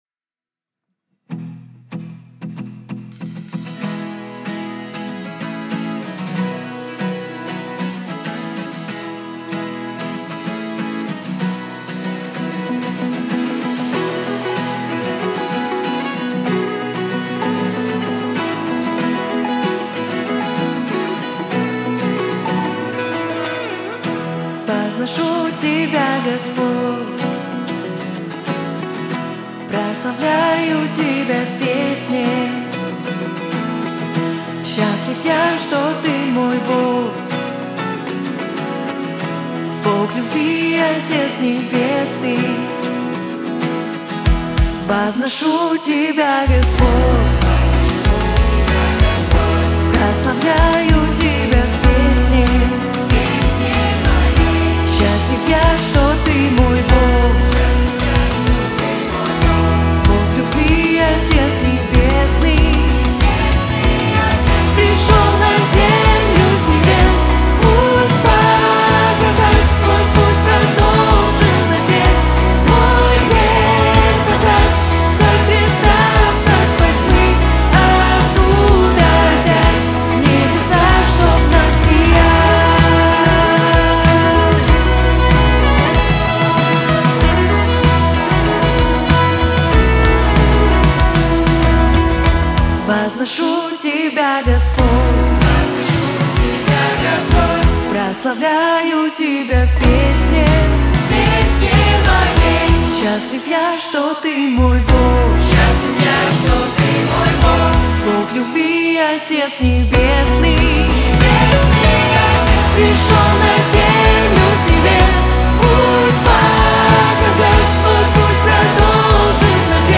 354 просмотра 1863 прослушивания 49 скачиваний BPM: 95